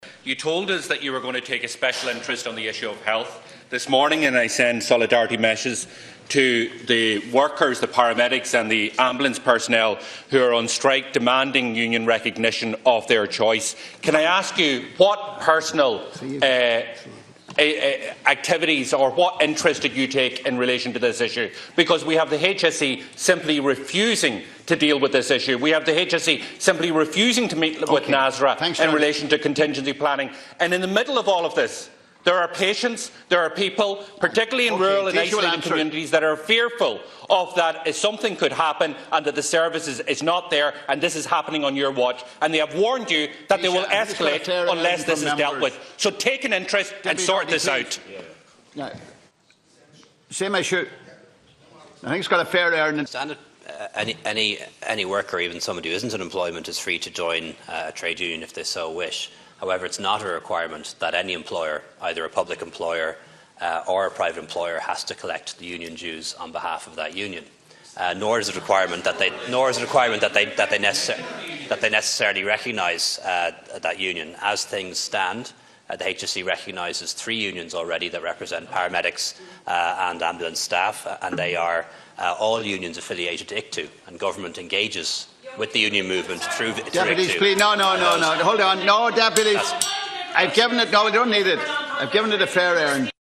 Speaking in the Dail, Deputy Pearse Doherty says people in rural communities, are fearful that a vital service wouldn’t be available in an emergency situation.